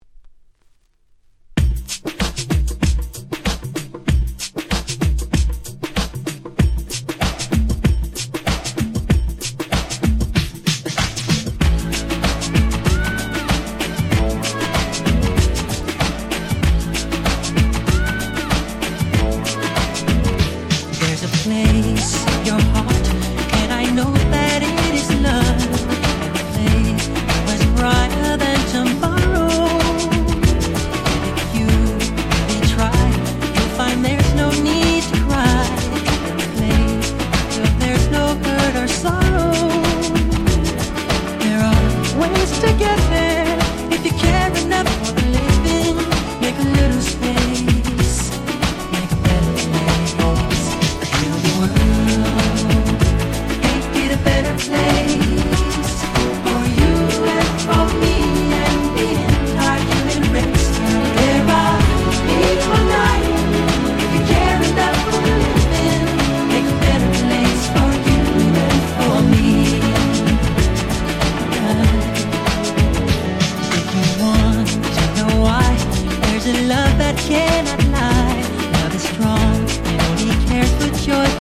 90's R&B